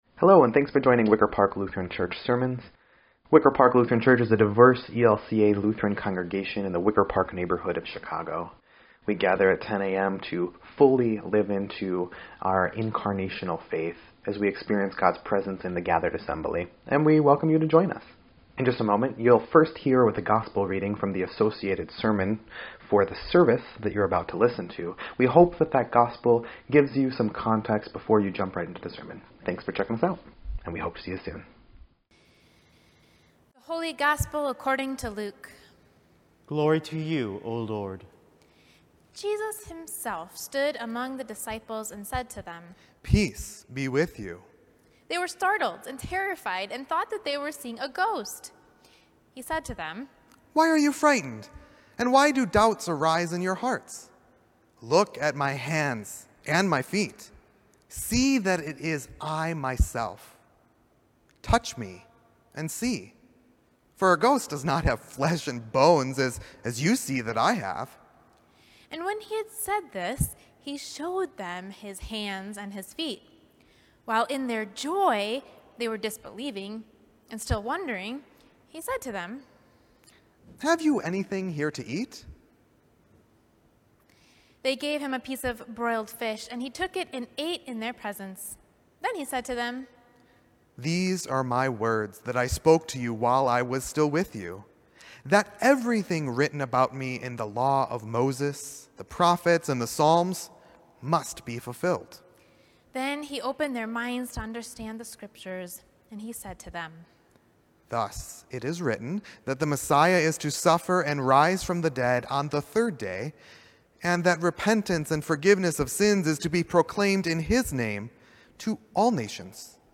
4.18.21-Sermon_EDIT.mp3